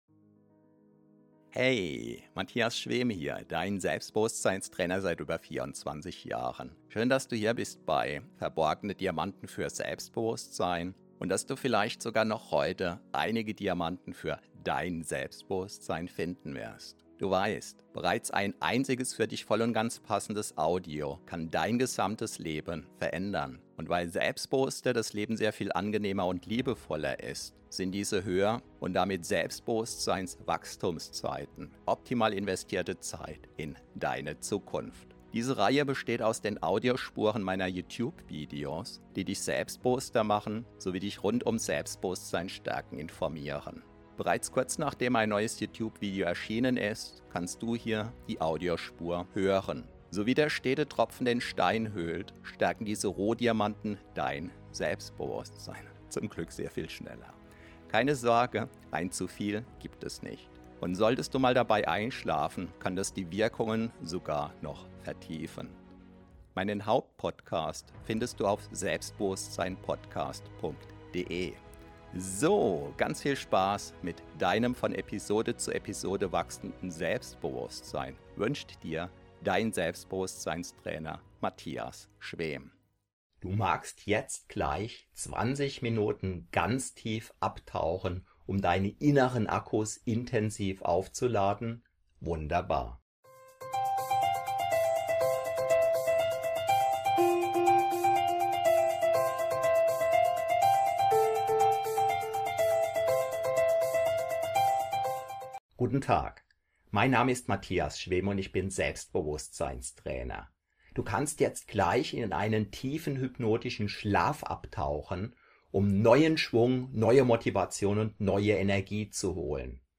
Sofort Power Nap 20 min inkl. Alarm!